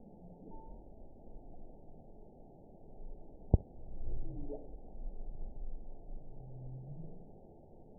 event 917755 date 04/15/23 time 01:32:36 GMT (2 years ago) score 8.13 location TSS-AB03 detected by nrw target species NRW annotations +NRW Spectrogram: Frequency (kHz) vs. Time (s) audio not available .wav